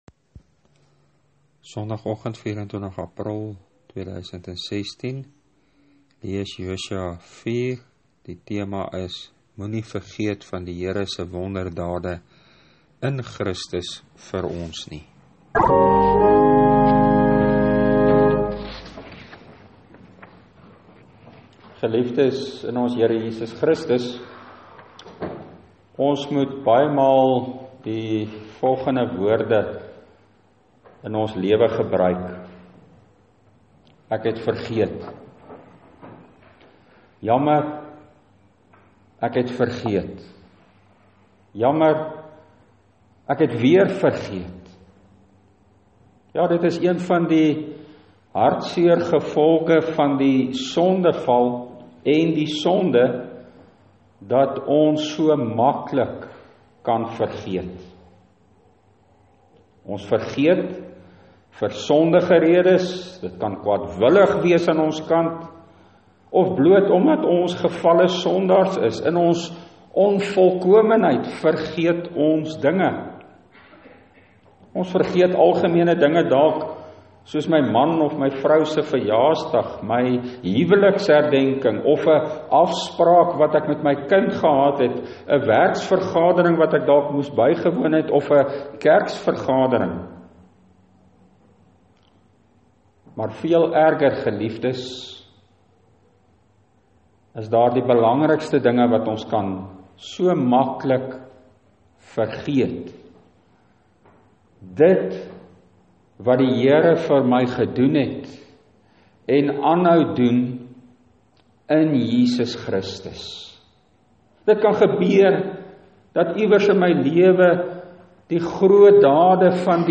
Preekopname (GK Carletonville, 2016-04-24):